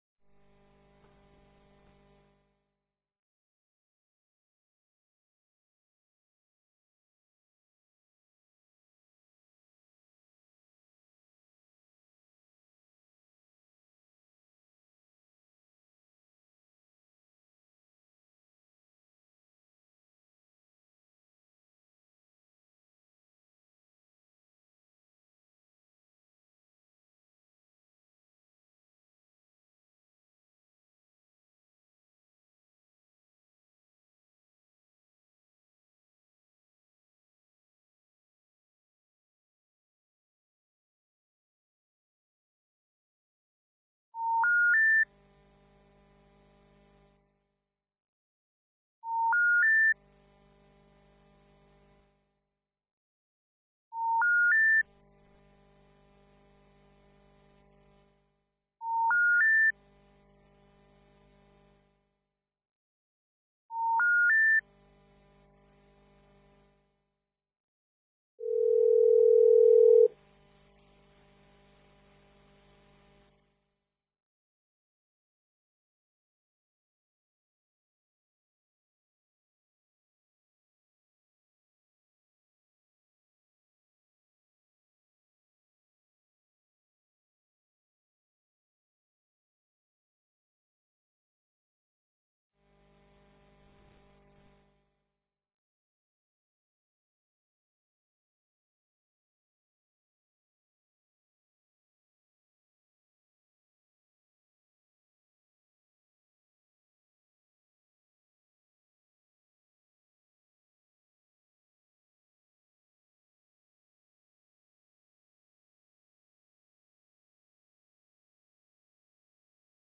Seduta del consiglio comunale - 24.06.2025